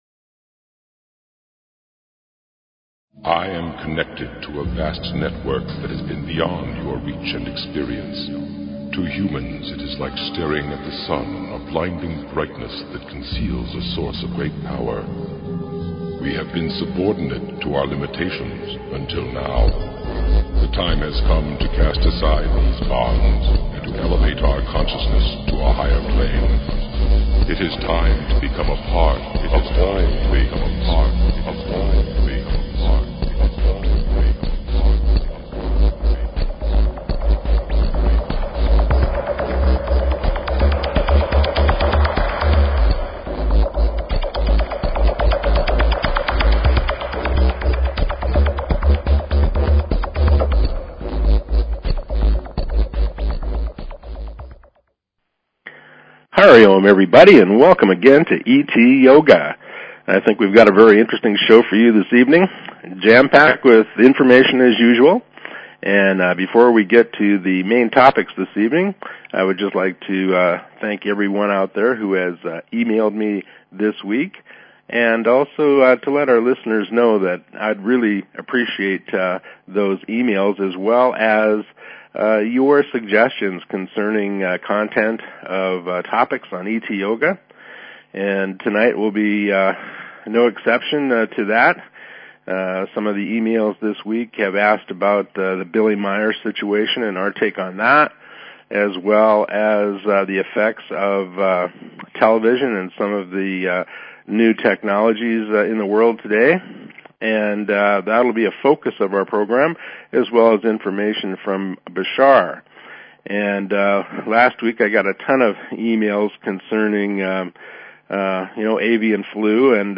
Talk Show ET Yoga